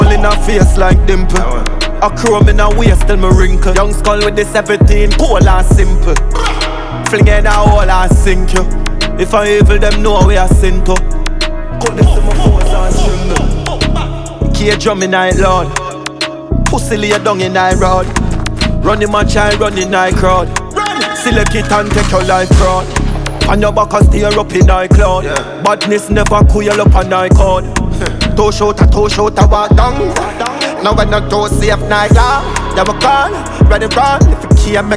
Жанр: Танцевальные / Реггетон